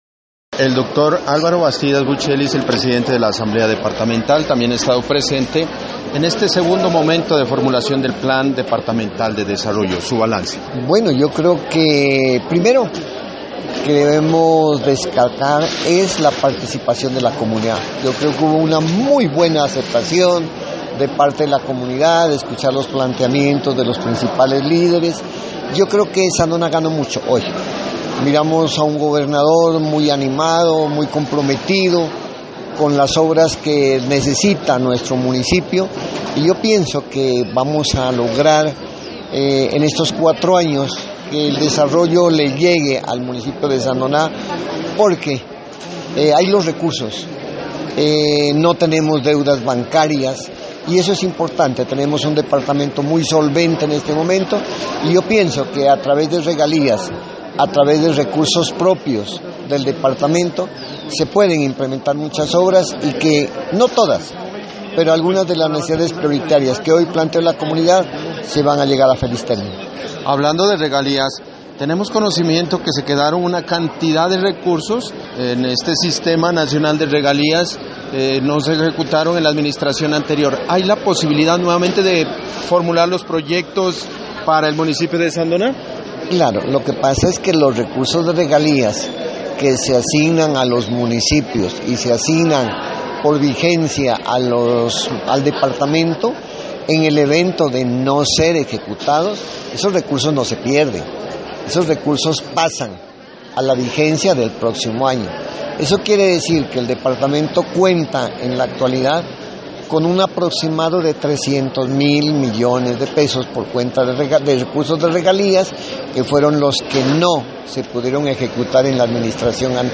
Entrevista con el presidente de la Asamblea Departamental Álvaro Bastidas Bucheli: